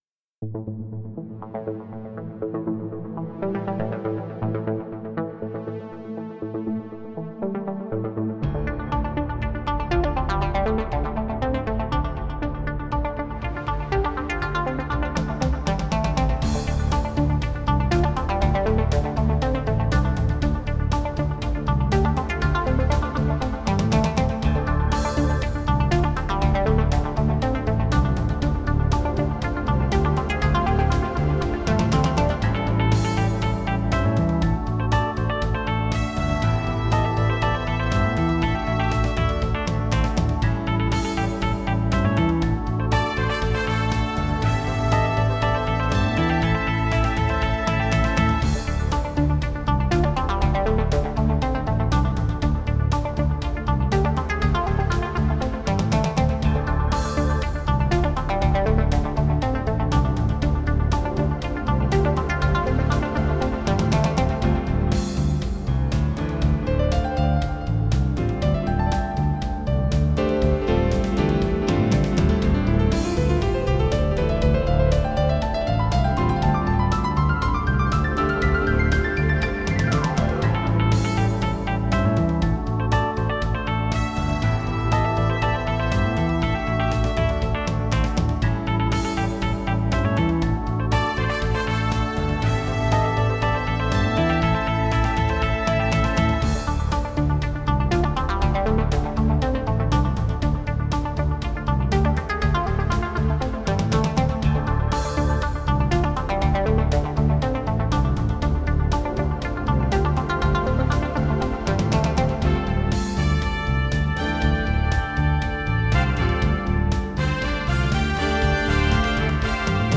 Audio with Lead-solo (part of the pack):